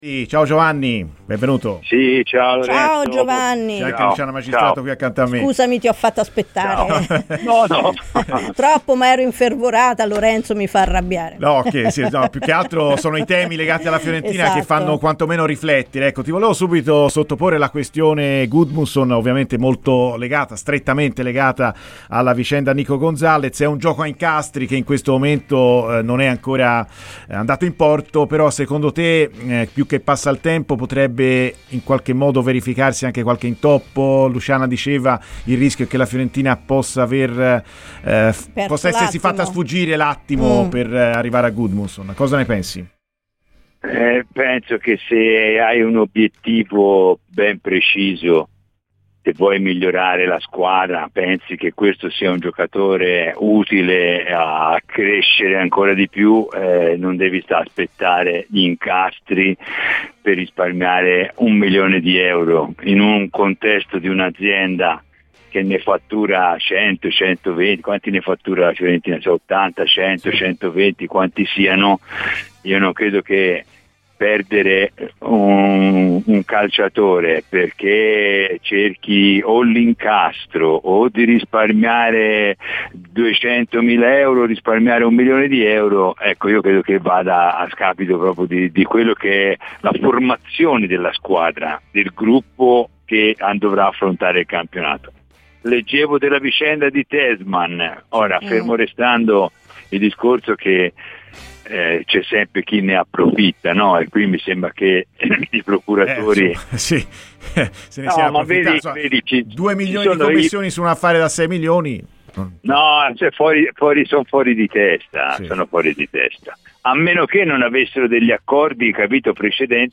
Giovanni Galli, ex portiere ed ex dirigente della Fiorentina, ha parlato nel corso di "Viola Amore Mio" in onda su Radio FirenzeViola soffermandosi sul mercato e soprattutto sui portieri viola: